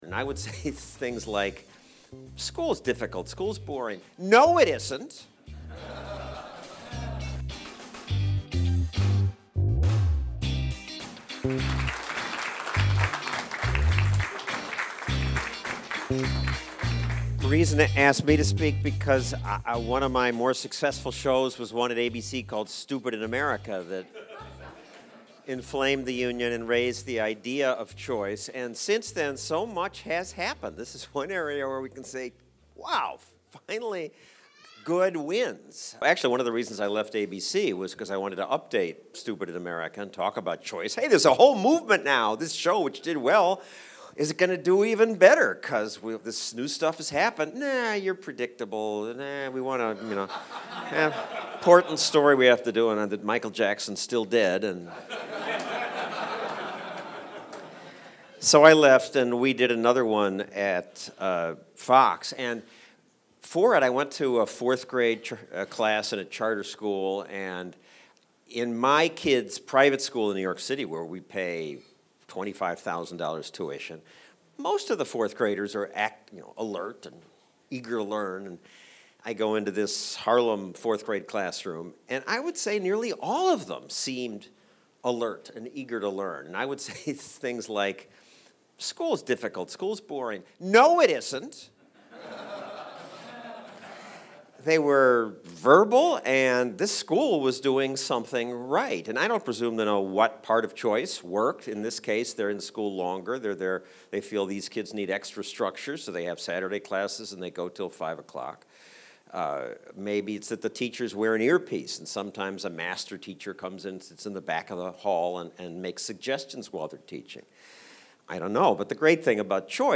Stossel made a speech at Reason Weekend 2012, Reason Foundation's annual donor event, about the successes of freedom in education. He talked about trying to update his 20/20 special, "Stupid in America," and not being able to do it, visiting Harlem schools full of kids ready to learn and why libertarians oppose a single payer system in health-care but cheer it in education.